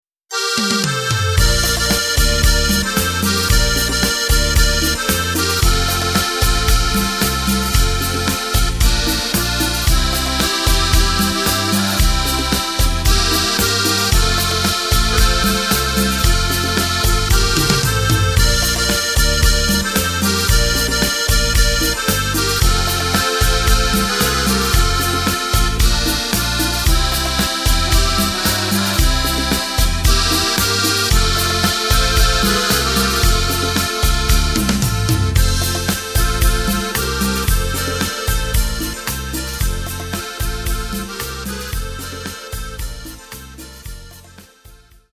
Takt:          4/4
Tempo:         113.00
Tonart:            F
Schlager-Rumba!
Playback mp3 Demo